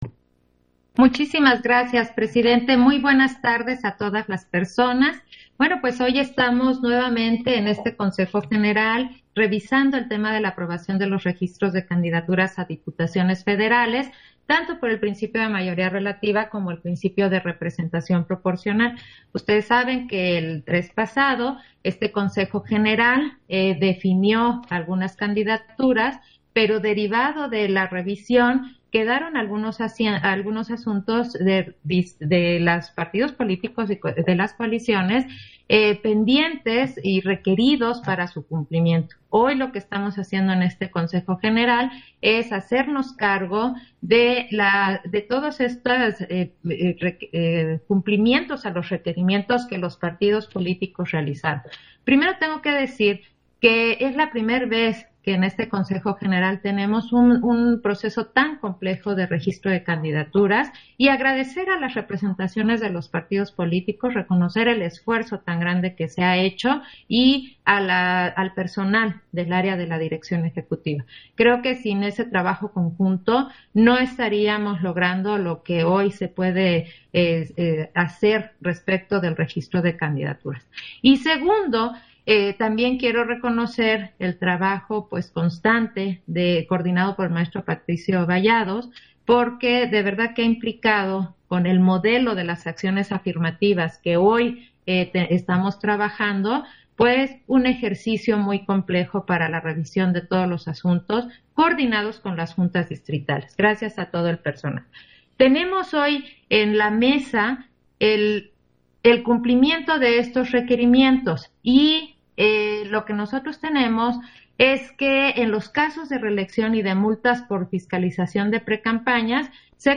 Intervención de Claudia Zavala, en Sesión Extraordinaria, en el punto relativo por el que se registran candidaturas a diputaciones por el principio de mayoría relativa y representación proporcional para las Elecciones 2021